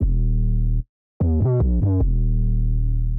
Index of /archive/TRUTH-HZ/2023_TRUTH-HZ_USB/Sample Packs/Crowdsourced Sample Pack/Drum Kit/808s & Subs